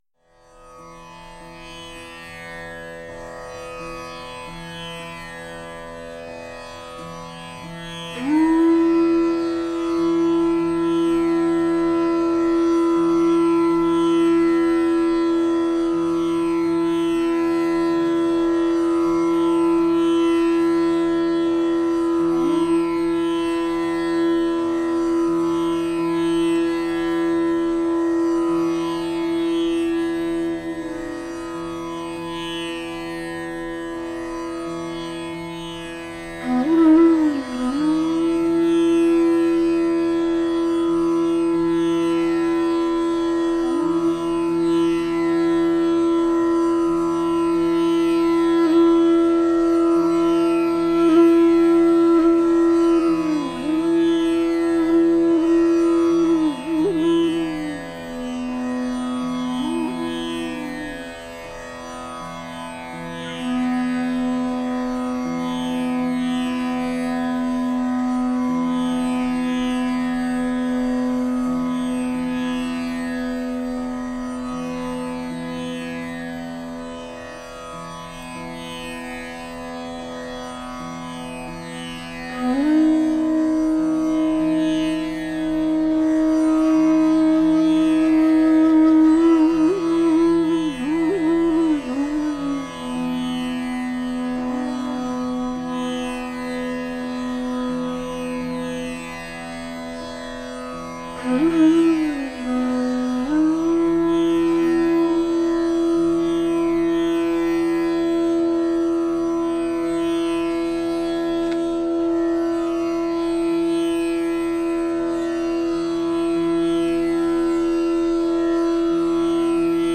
Flute-Raga-Brindabani.mp3